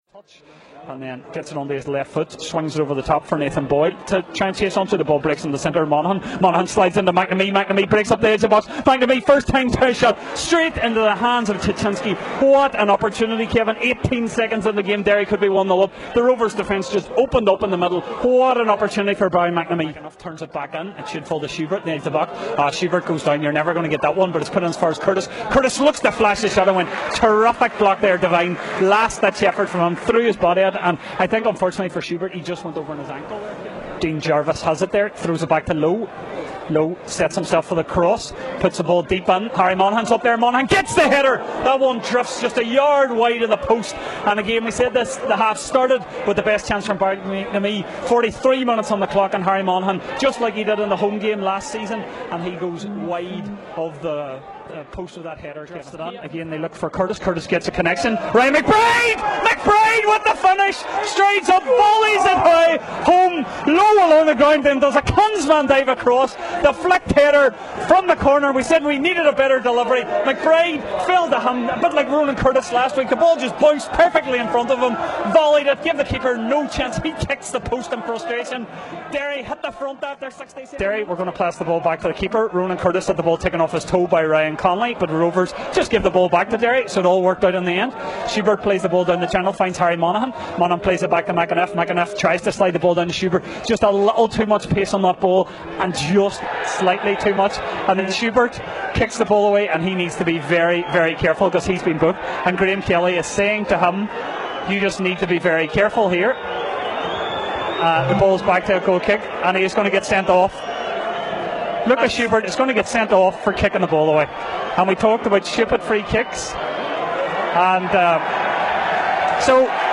Derry City left Tallaght on Friday night with 3 points courtesy of a 68th Min Goal from Captain Ryan McBride. We have match highlights plus the gaffers thoughts soon after the final whistle.